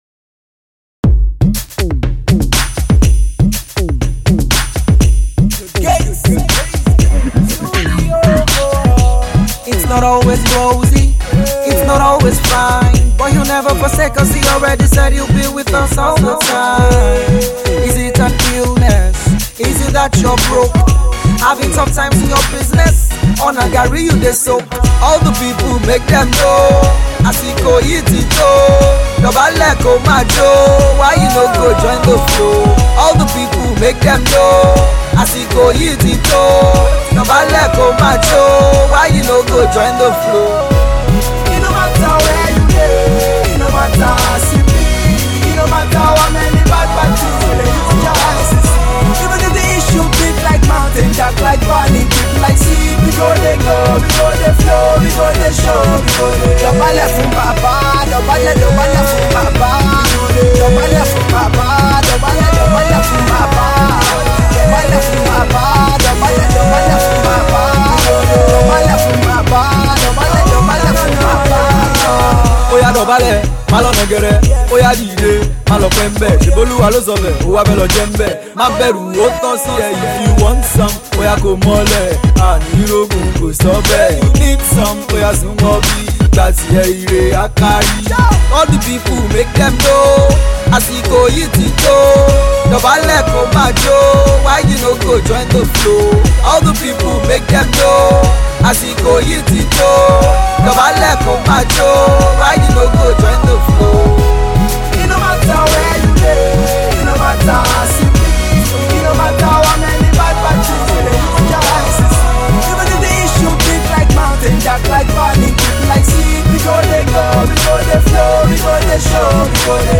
Afro-Fusion rapper/singer
he takes the more feel good mid-tempo route